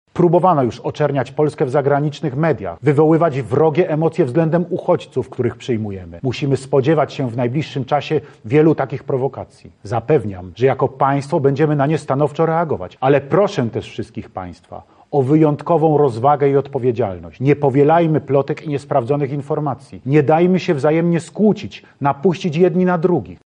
Prezydent Polski w swoim orędziu ostrzega przed dezinformacją.